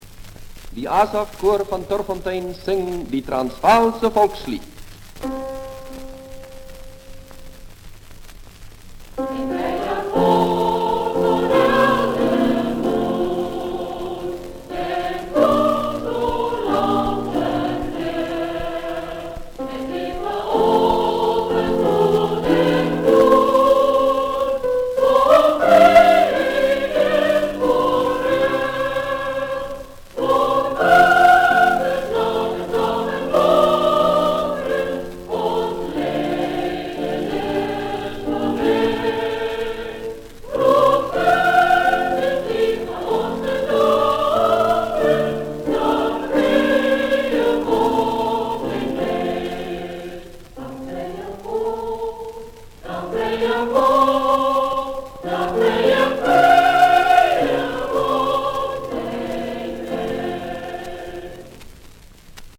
recorded 1938
78 rpm
ASAF CHOIR (KOOR) - choir